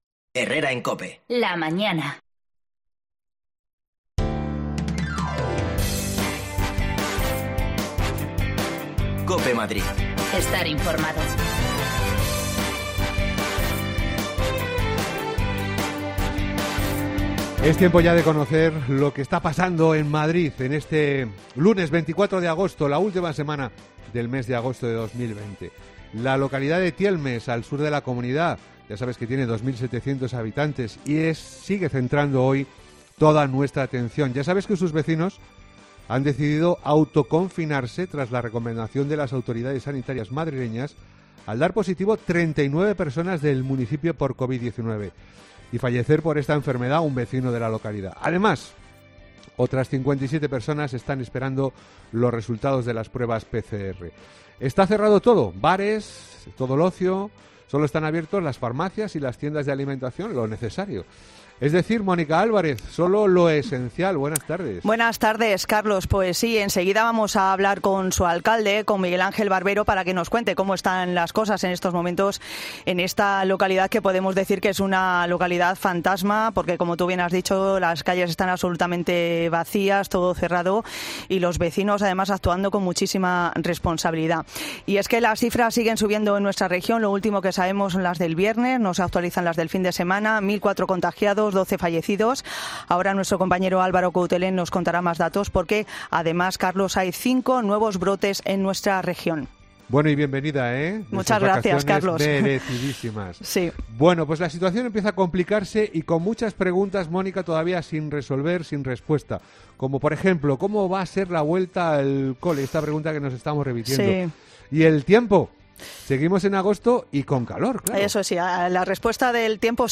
En Tielmes se han autoconfinado por el aumento de contagios en la localidad. Hablamos con Miguel Ánguel Barbero, su alcalde
Las desconexiones locales de Madrid son espacios de 10 minutos de duración que se emiten en COPE, de lunes a viernes.